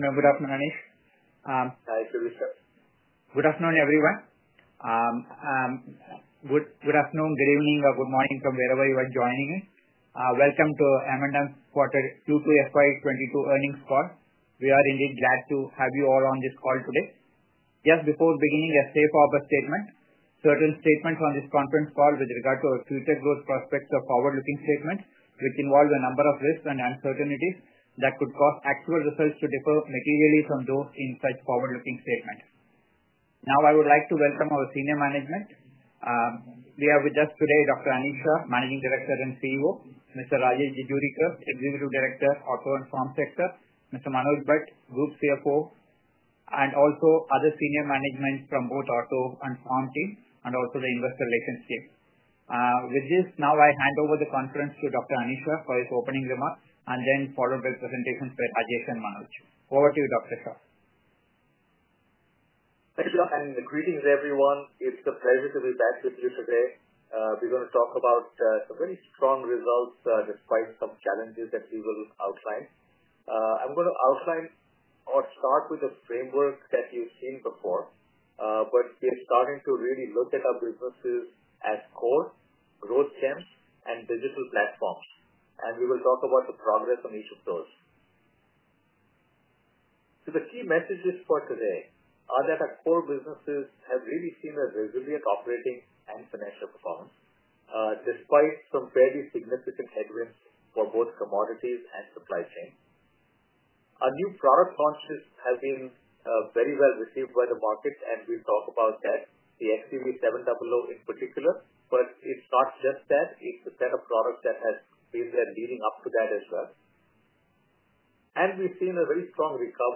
AUDIO – M&M Q2FY22 Earnings Call – 9th November 2021